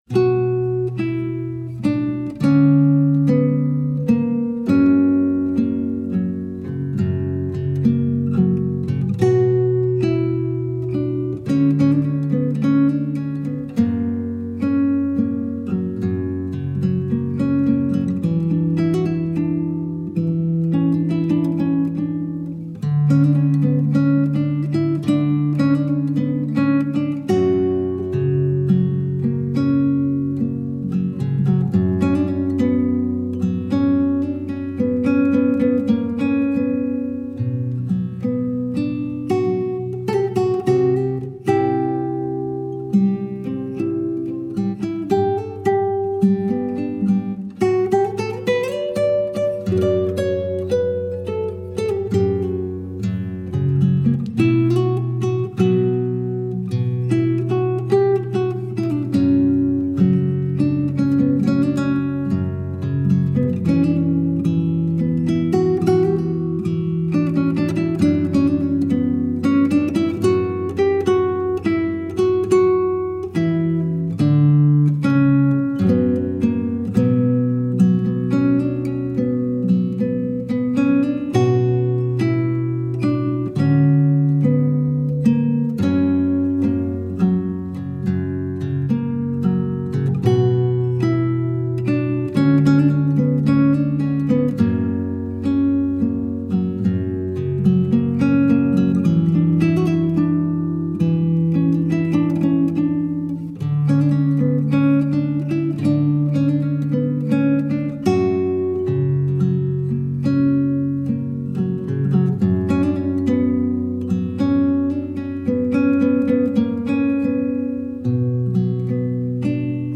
موسیقی بی کلام Classical Crossover آرامش بخش عاشقانه گیتار